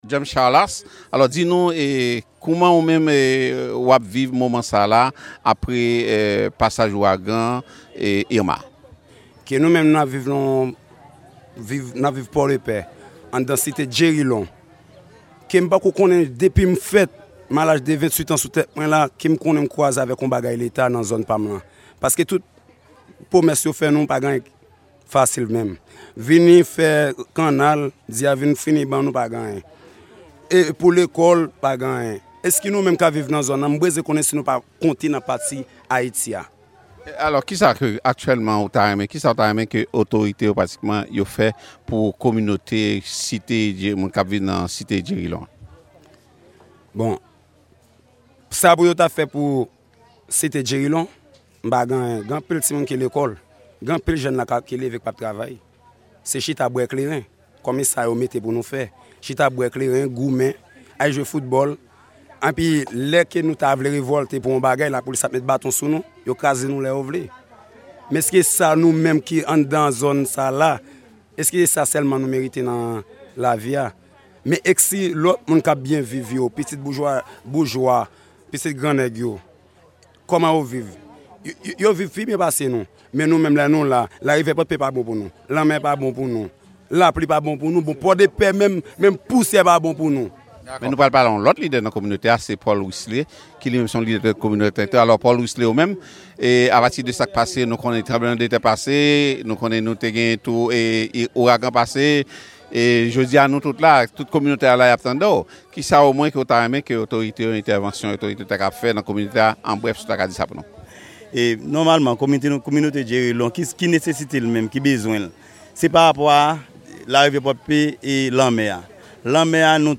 Temwayaj kèk jèn nan Pòdepè